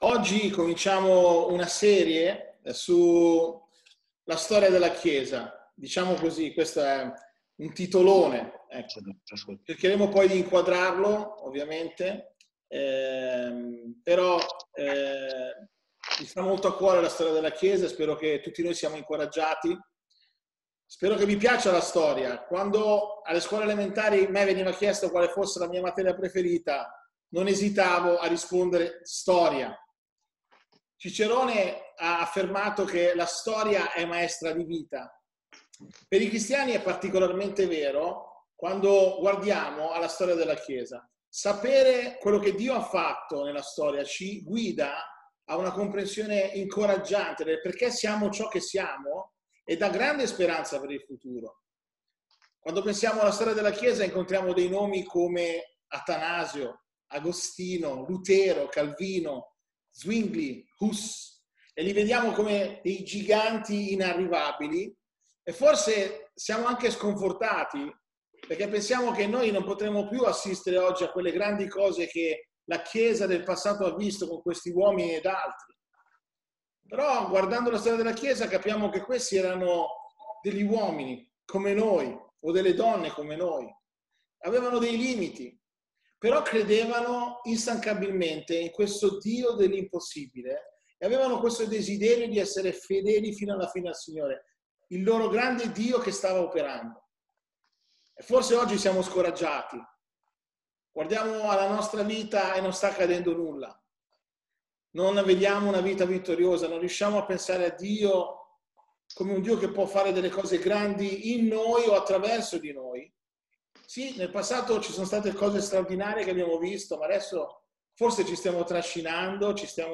Tutti i sermoni S1.